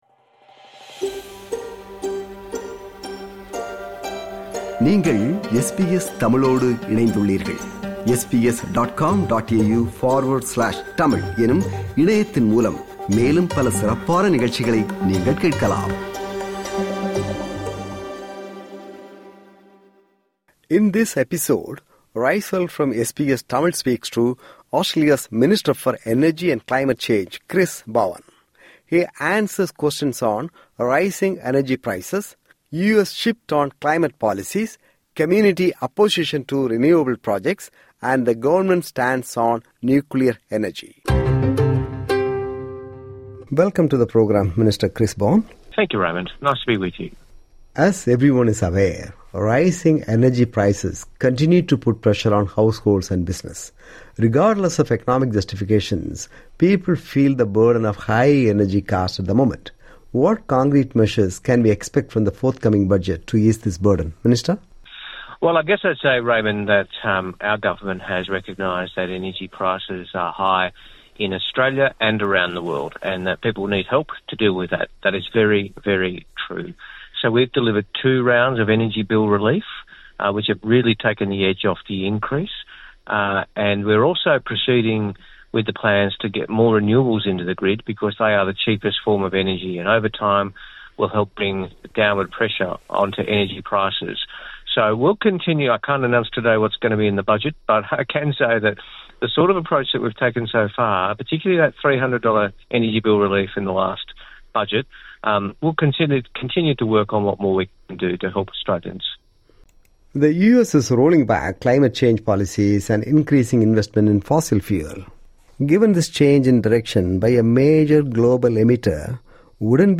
speaks to Australia's Minister for Energy and Climate Change, Chris Bowen. He answers questions on rising energy prices, the U.S. shift on climate policies, community opposition to renewable projects, and the government's stance on nuclear energy.